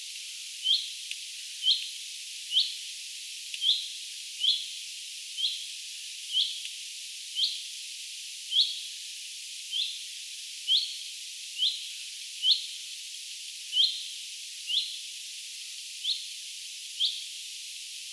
peipon hyit-ääniä
Nehän on myös jotain peipon huomioääniä.
peipon_hyit-aania.mp3